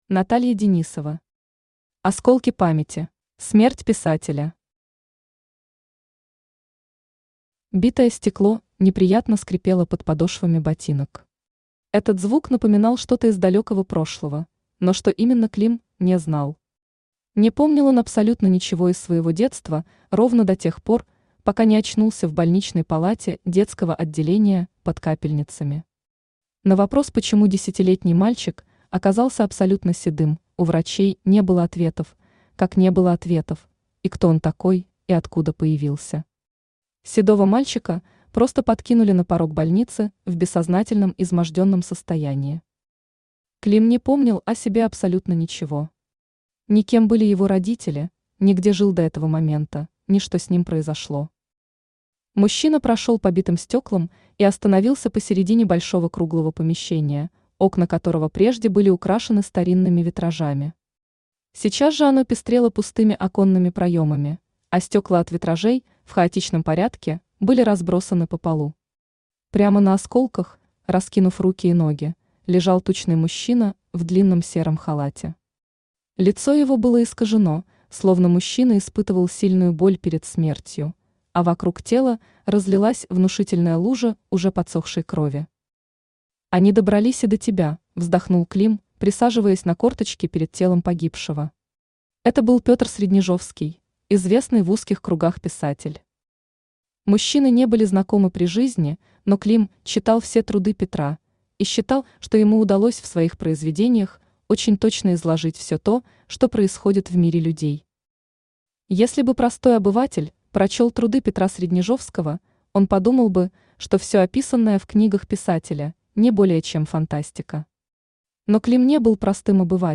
Aудиокнига Осколки памяти Автор Наталья Денисова Читает аудиокнигу Авточтец ЛитРес.